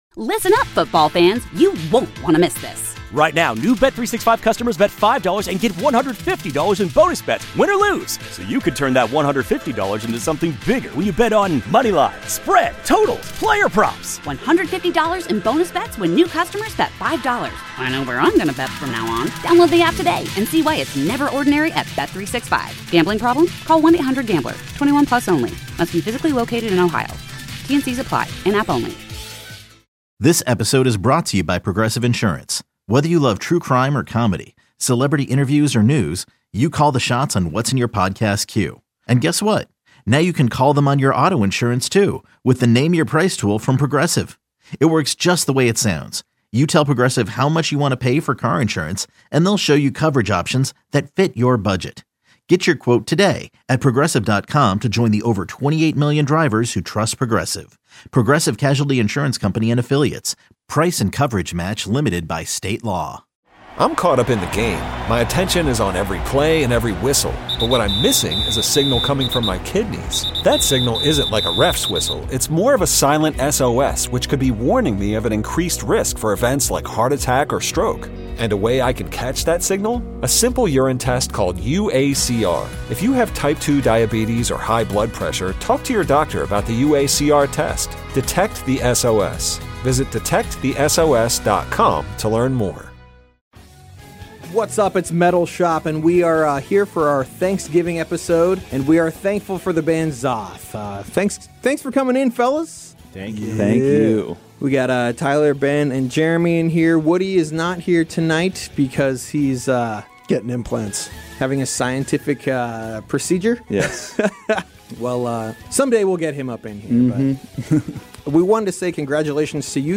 Hail Santa 9 is going down on December 7th and 8th at the Highline and it features a ton of amazing bands such as XOTH, Morta Skuld, Aethereus, Eight Bells, Petrification and many more! We spoke with the members of XOTH who are the masterminds behind this Northwest Christmas metal tradition....